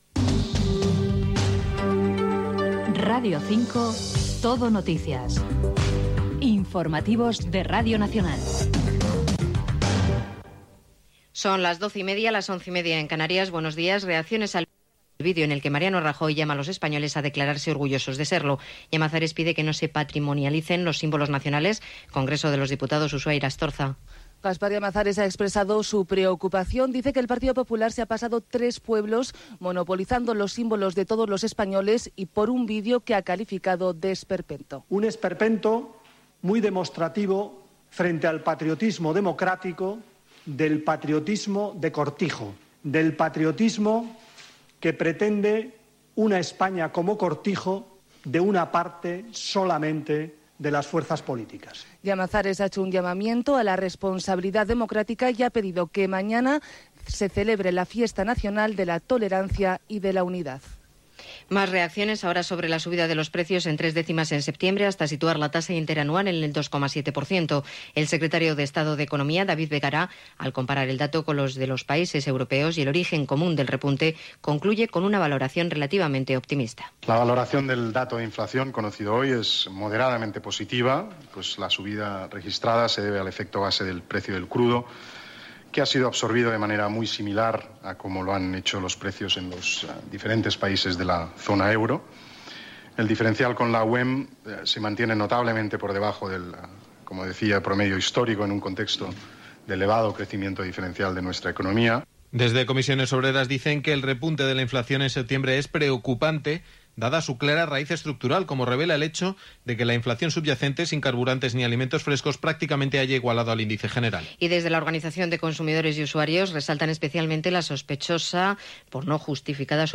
Indicatiu de l'emissora, declaracions de Llamazares sobre un vídeo del PP sobre el dia de la Hispanitat, dada de la inflació.
Informatiu